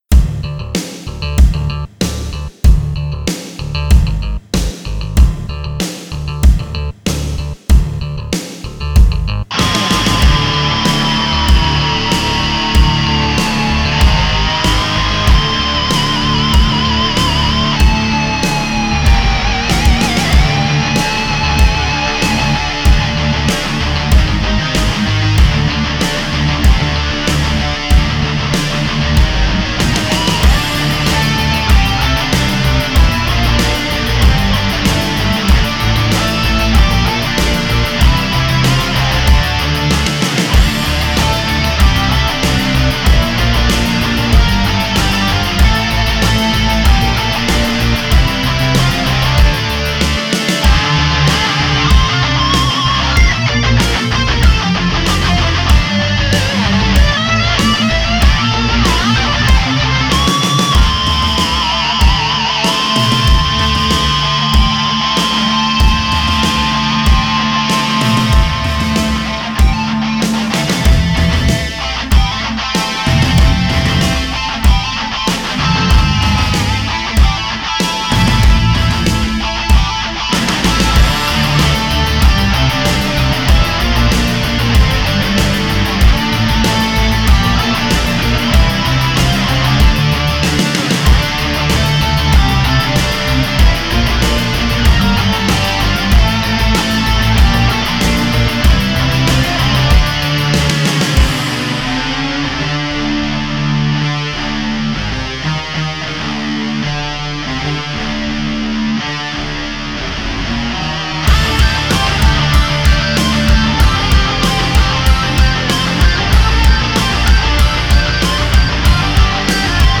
metal remix